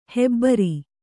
♪ hebbari